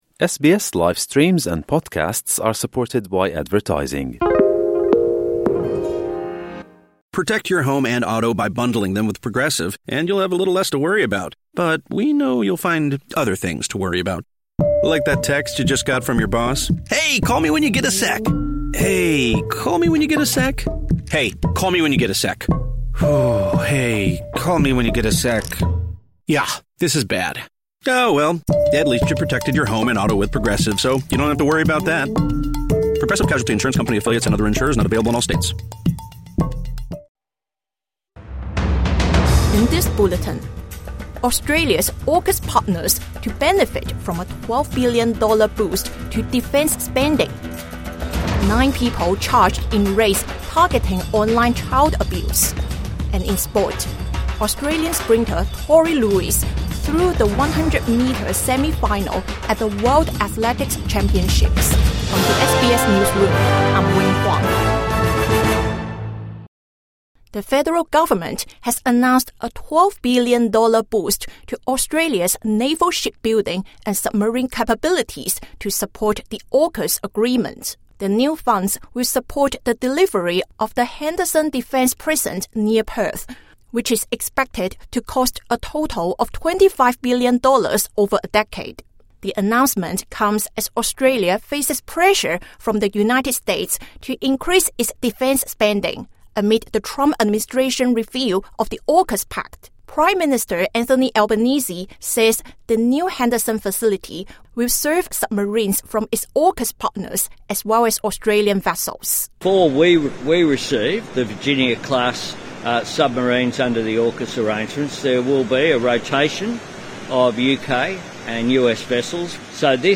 Australia announces more defence funding| Evening News Bulletin 14 September 2025